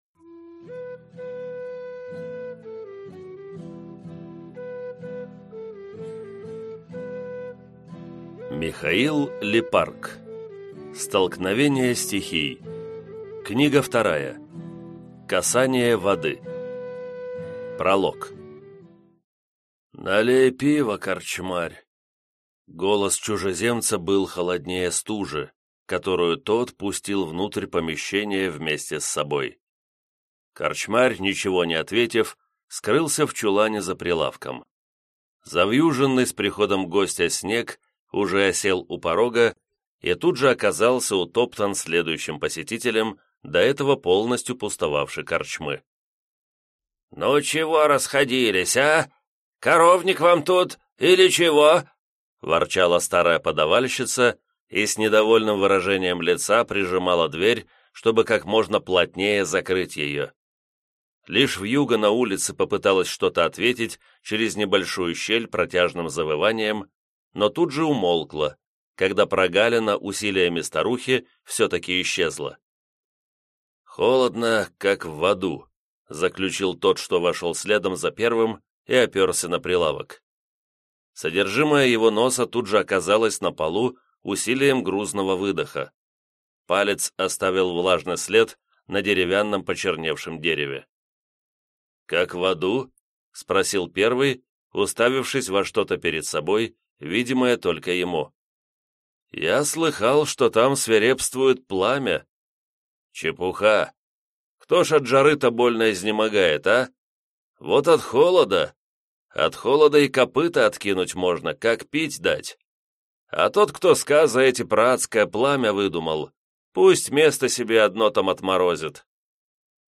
Аудиокнига Столкновение стихий. Книга 2. Касание Воды | Библиотека аудиокниг